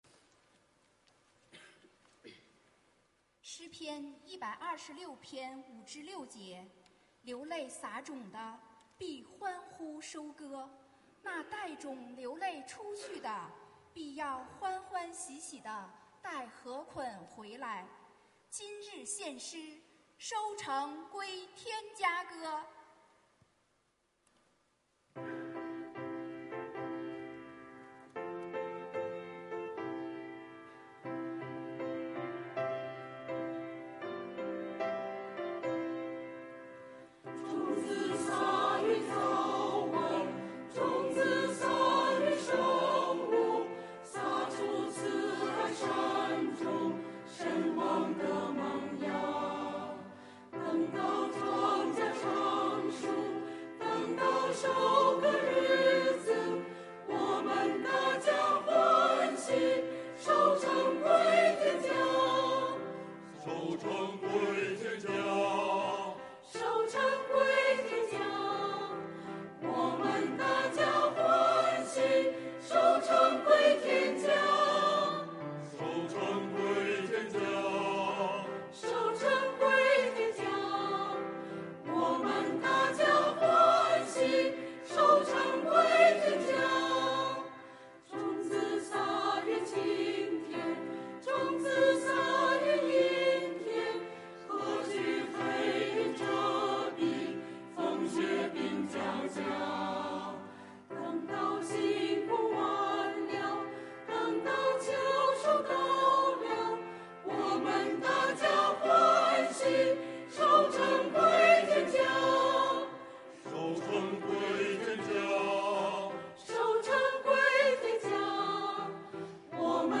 （阿们） 团契名称: 联合诗班 新闻分类: 诗班献诗 音频: 下载证道音频 (如果无法下载请右键点击链接选择"另存为") 视频: 下载此视频 (如果无法下载请右键点击链接选择"另存为")